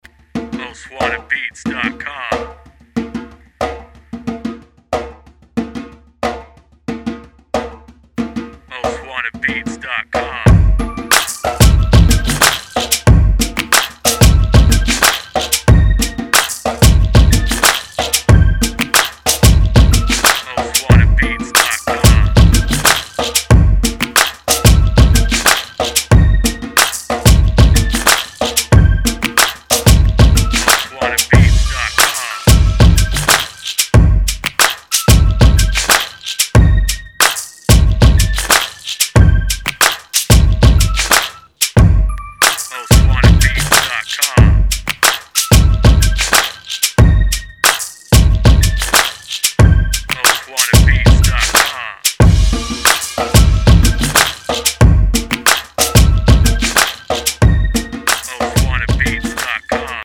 CLUB INSTRUMENTAL